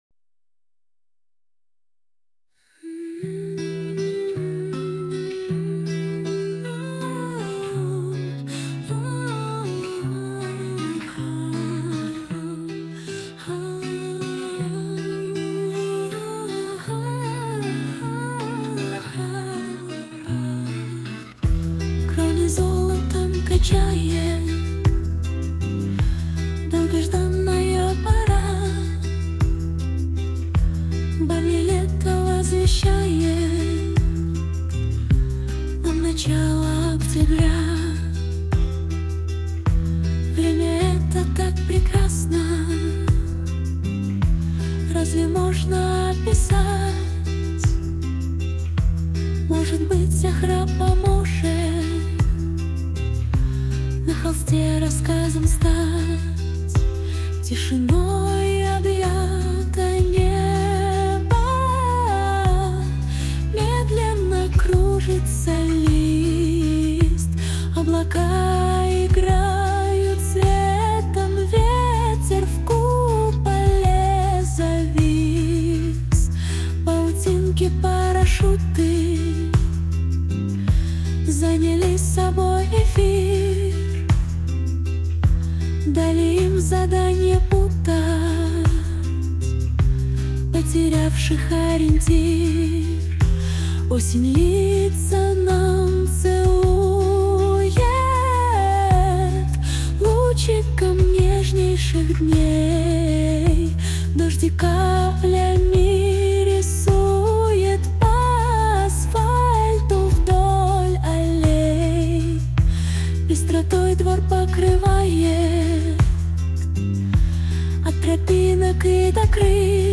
́́́́🧡🤎💛❤ ВОЕННАЯ ОСЕНЬ… (песня)
Военная осень (поём стихи) .mp3